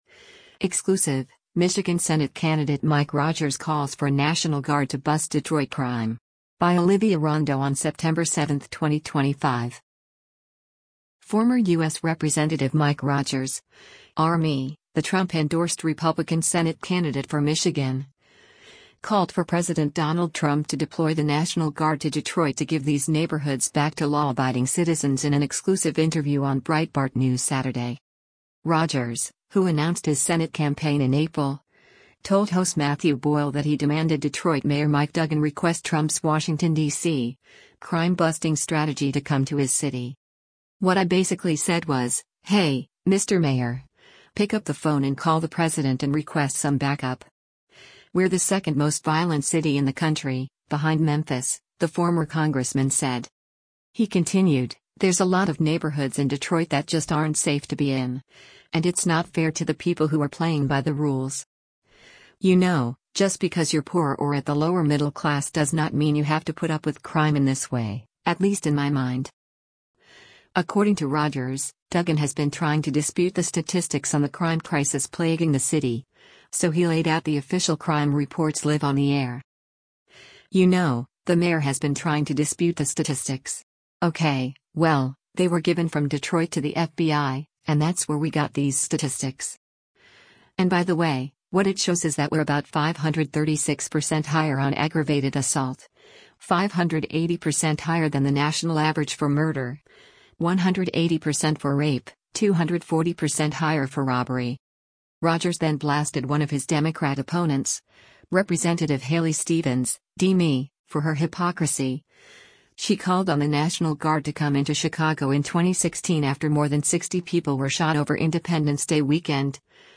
Former U.S. Rep. Mike Rogers (R-MI), the Trump-endorsed Republican Senate candidate for Michigan, called for President Donald Trump to deploy the National Guard to Detroit to “give these neighborhoods back” to law-abiding citizens in an exclusive interview on Breitbart News Saturday.